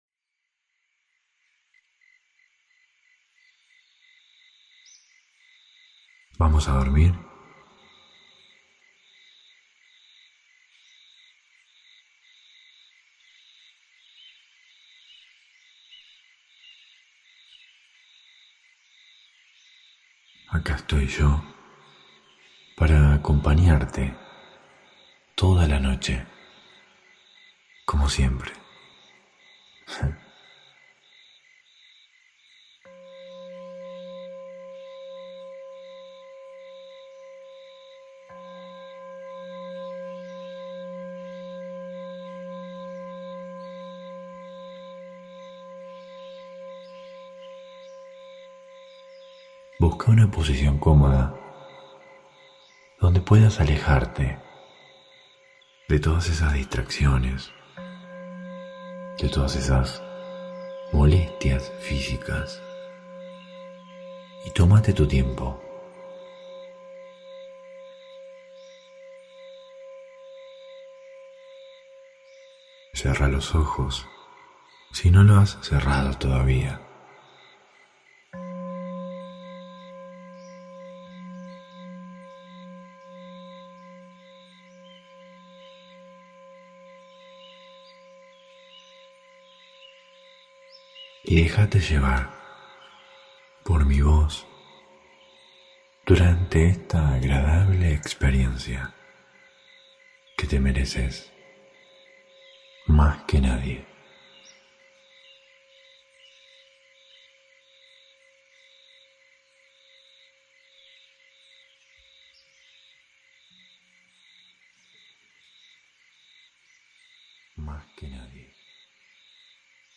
Hipnosis guiada para dormir. [Altamente recomendable escucharlo con auriculares ] Hosted on Acast.